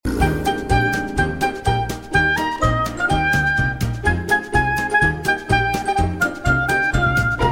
Kategorie Świąteczne